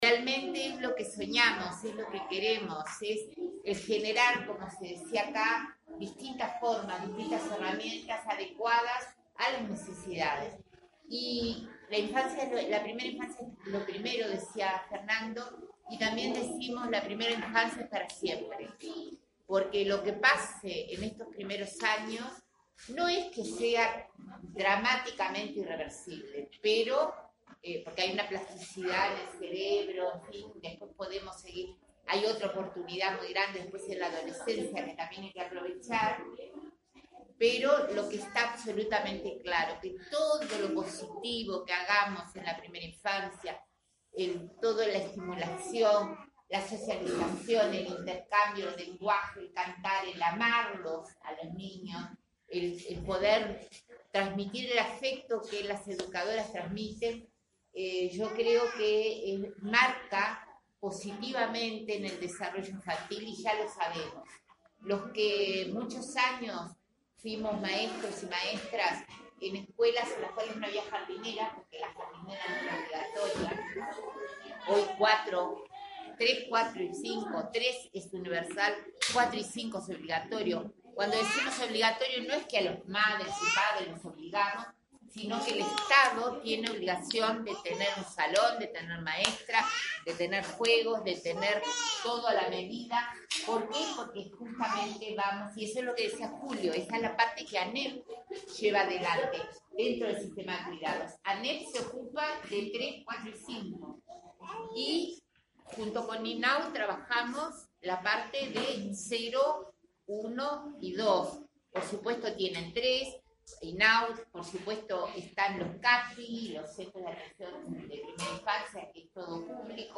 “La primera infancia es para siempre”, subrayó la ministra de Desarrollo Social, Marina Arismendi, refiriéndose a que todo lo positivo que se haga en esta etapa marca positivamente el desarrollo infantil. La jerarca participó de la inauguración de una casa comunitaria en el Municipio A en Montevideo.